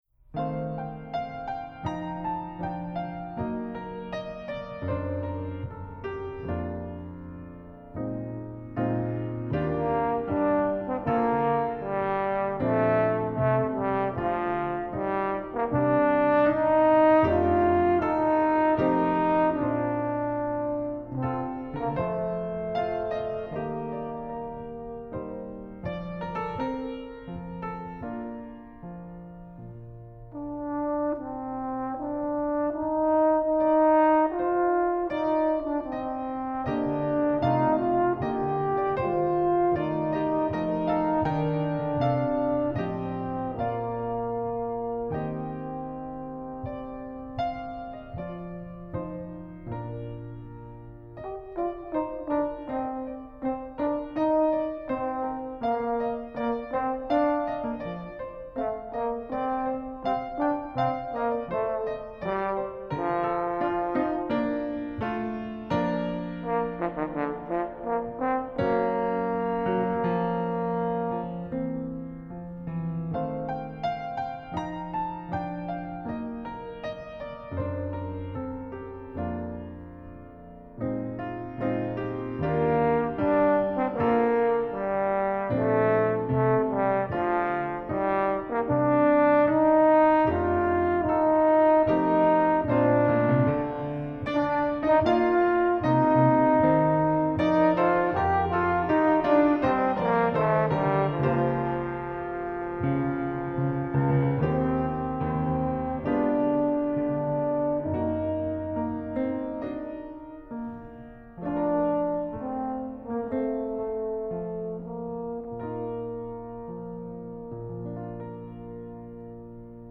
French Horn and Piano